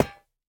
Minecraft Version Minecraft Version snapshot Latest Release | Latest Snapshot snapshot / assets / minecraft / sounds / block / netherite / break2.ogg Compare With Compare With Latest Release | Latest Snapshot
break2.ogg